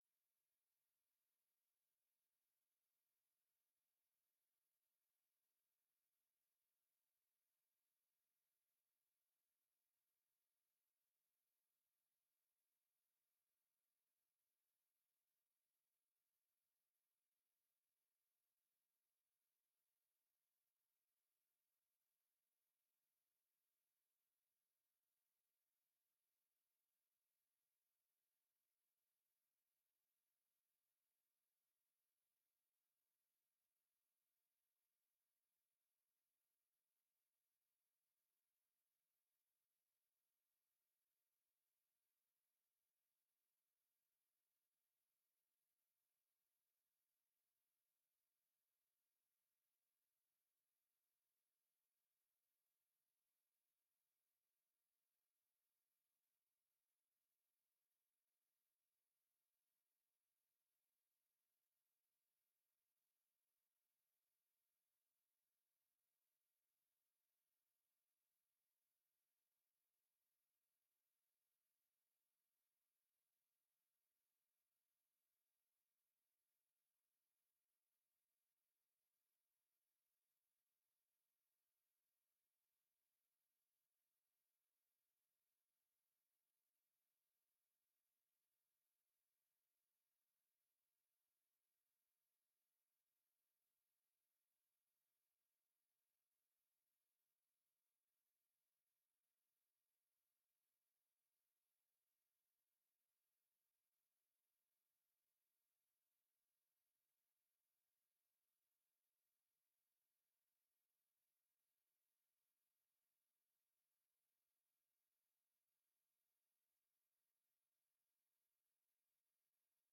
informatieve raadsbijeenkomst 22 februari 2024 19:30:00, Gemeente Doetinchem
Agendapunt 1 vindt plaats in het werkcafé. Agendapunt 2 start in de raadzaal.